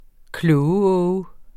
Udtale [ ˈklɔːwəˌɔːwə ]